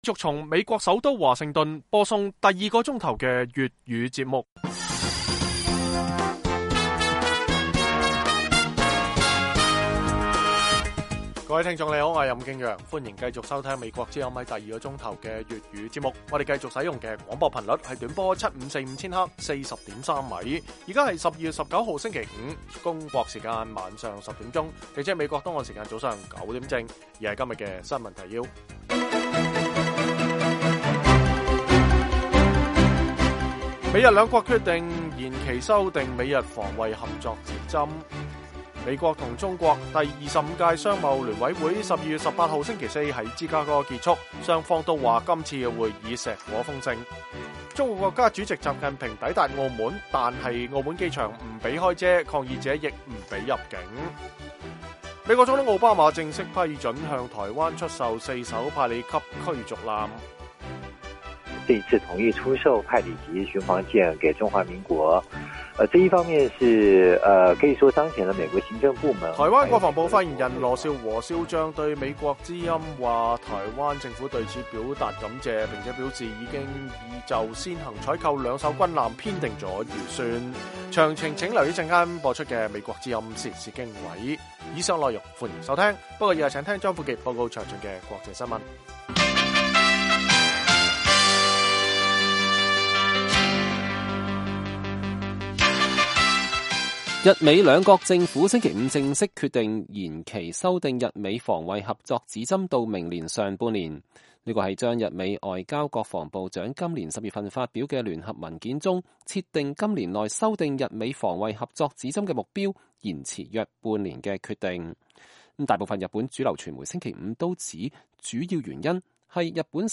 粵語新聞 晚上10-11點
每晚 10點至11點 (1300-1400 UTC)粵語廣播，內容包括簡要新聞、記者報導和簡短專題。